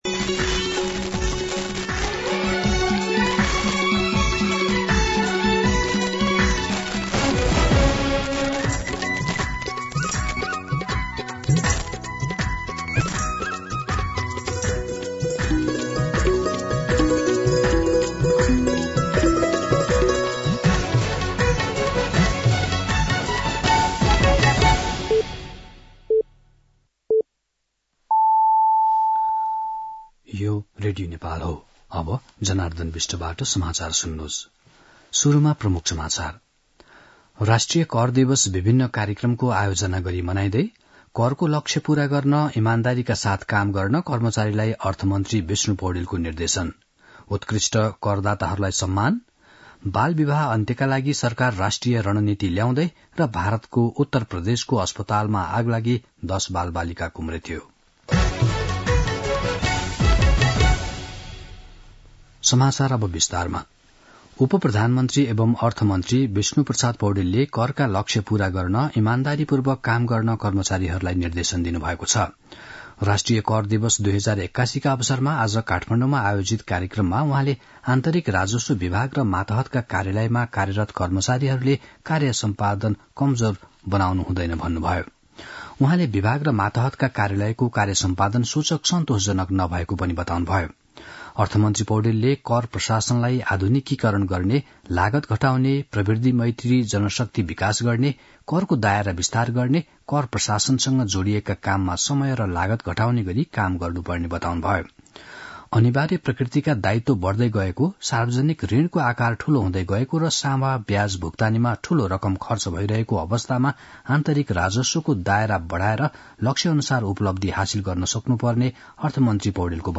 दिउँसो ३ बजेको नेपाली समाचार : २ मंसिर , २०८१
3-pm-Nepali-News-2.mp3